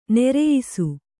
♪ nereyisu